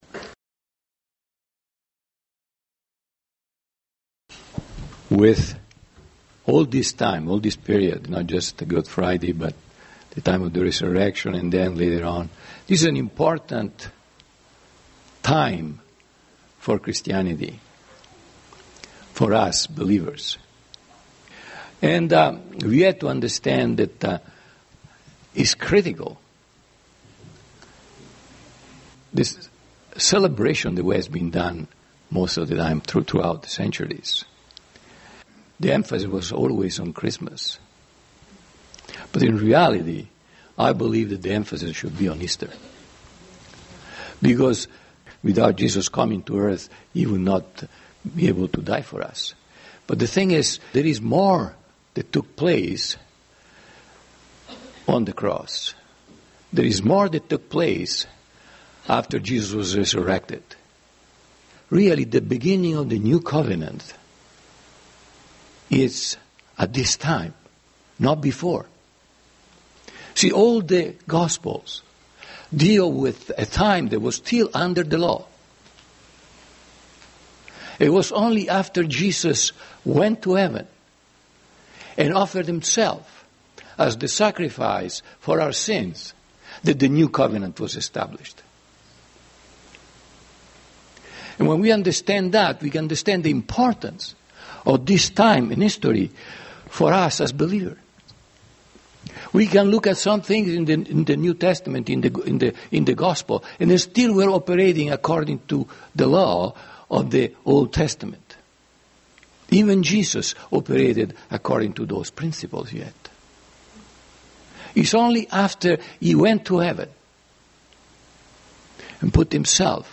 Easter Sunday Service and the Resurrection Power